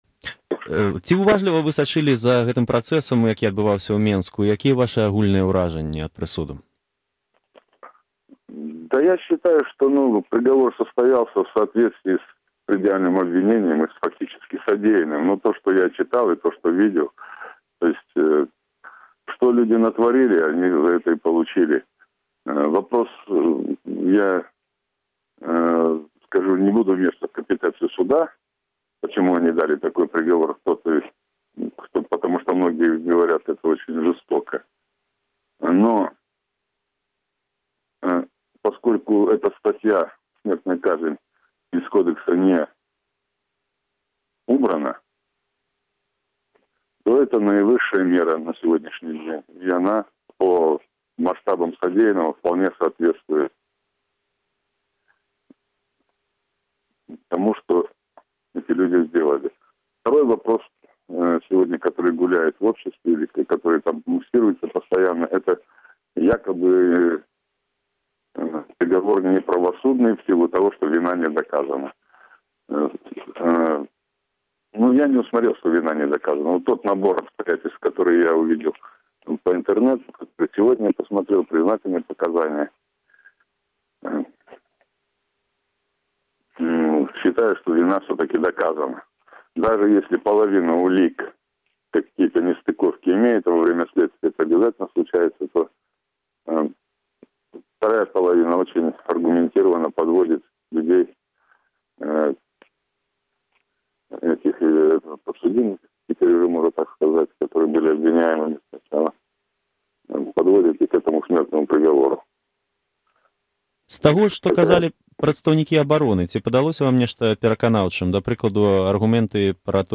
Гутарка з Алегам Алкаевым, 1 сьнежня 2011 году